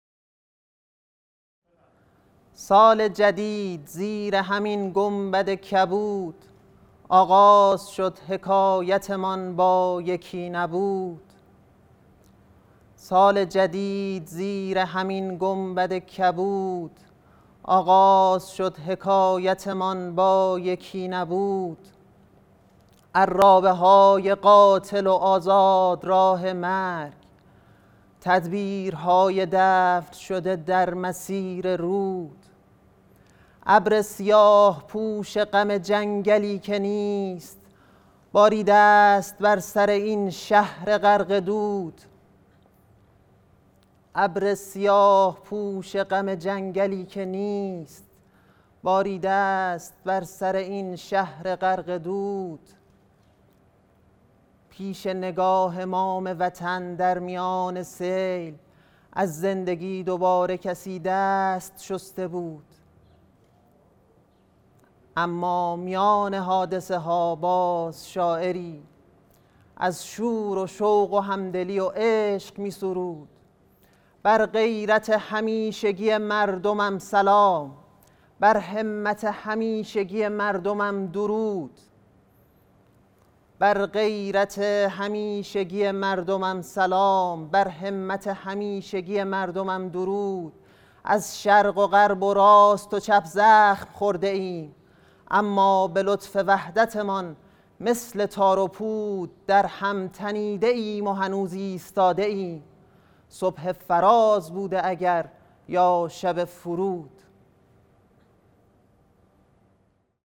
شعرخوانی | سال جدید زیر همین گنبد کبود ...
حرم حضرت معصومه (سلام الله علیها)_شهر مقدس قم | روایت هیأت فصل دوم